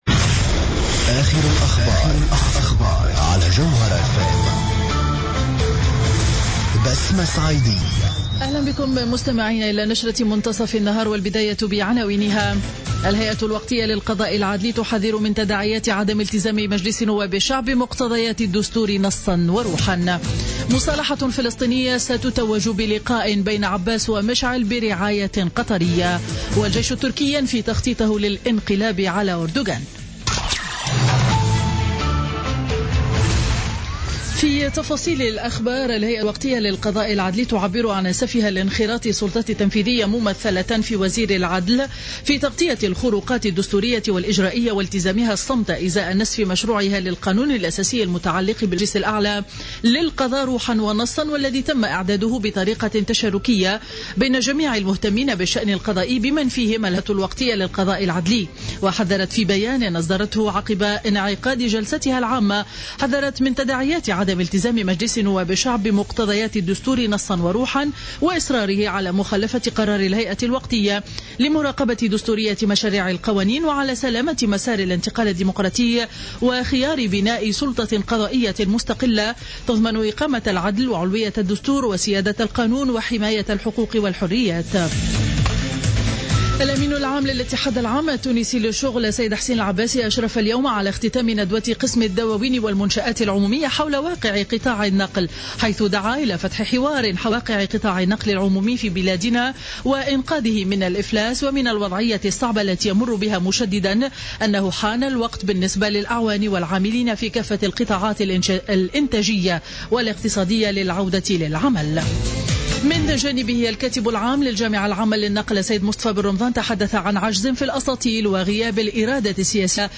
نشرة أخبار منتصف النهار ليوم الخميس 31 مارس 2016